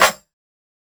Snare 005.wav